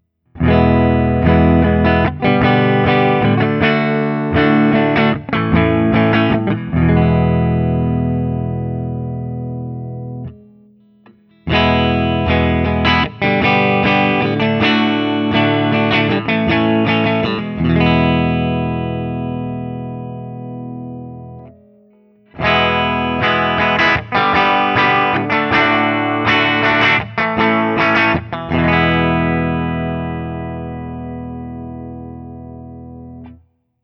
The guitar has a great jangly sound from those LB1 pickups and accentuating that is where I’d find myself the most happy with this instrument.
ODS100 Clean
Open Chords #2
This is the first time I used my new Axe-FX III for recording which I did direct to Audacity to my Mac Pro.
For each recording I cycle through the neck pickup, both pickups, and finally the bridge pickup. All knobs on the guitar are on 10 at all times.
Guild-TBird-ST-ODS100-Open2-1.wav